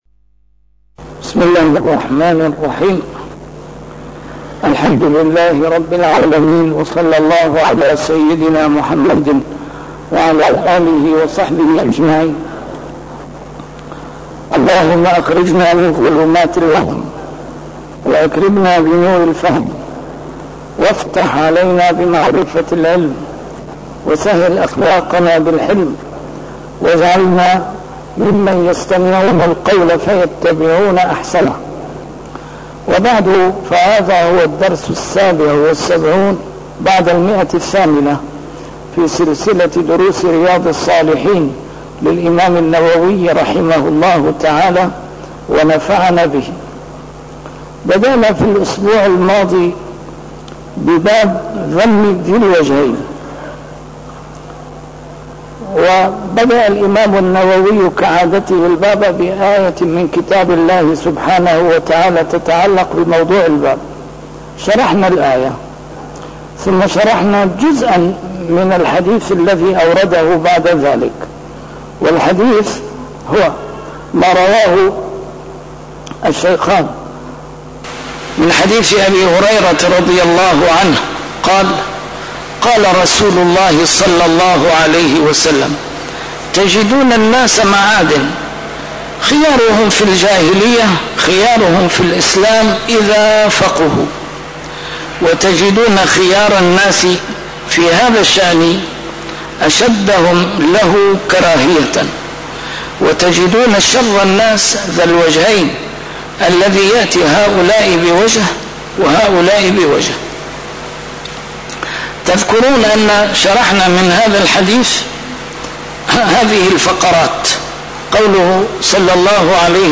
A MARTYR SCHOLAR: IMAM MUHAMMAD SAEED RAMADAN AL-BOUTI - الدروس العلمية - شرح كتاب رياض الصالحين - 877- شرح رياض الصالحين: ذم ذي الوجهين